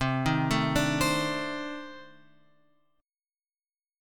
C Minor 6th Add 9th
Cm6add9 chord {8 6 7 7 x 8} chord